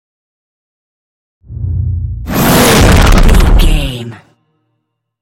Scifi whoosh to hit
Sound Effects
Atonal
futuristic
intense
woosh to hit